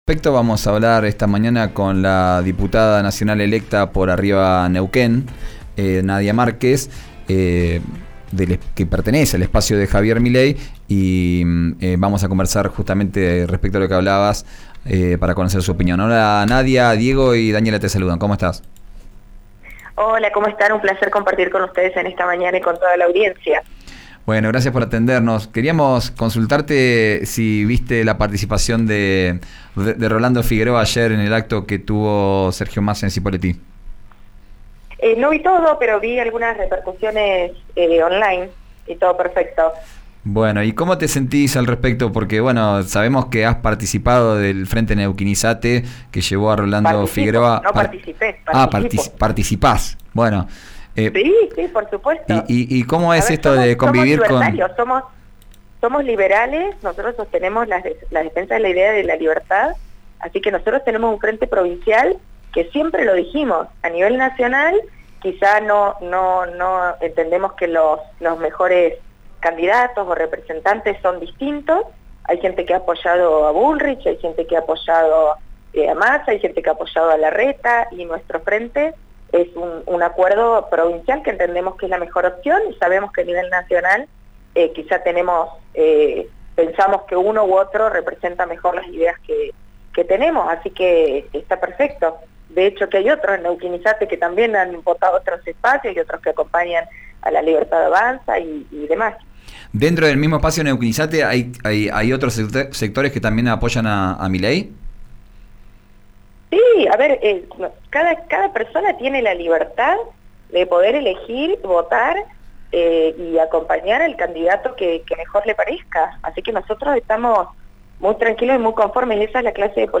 En diálogo con «Vos Al Aire» por RÍO NEGRO RADIO, dijo que no había visto todo el acto del ministro de Economía y postulante de Unión por la Patria, pero sí «algunas repercusiones».